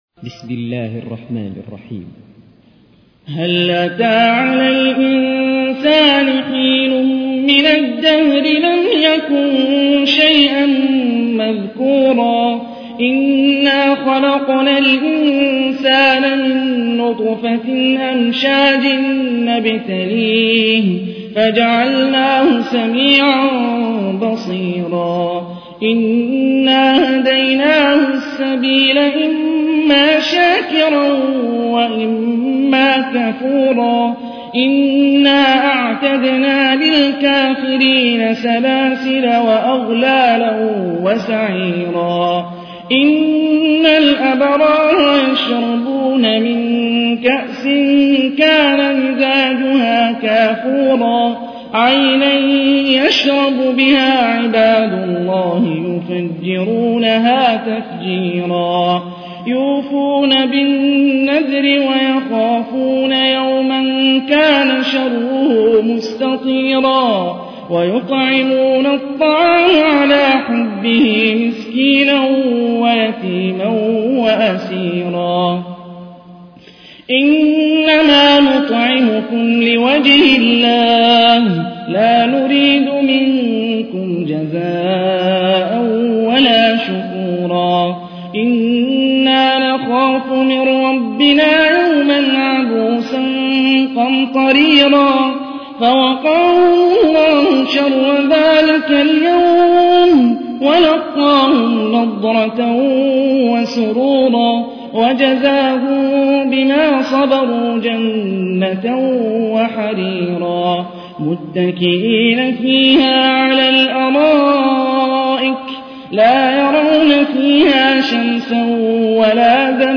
تحميل : 76. سورة الإنسان / القارئ هاني الرفاعي / القرآن الكريم / موقع يا حسين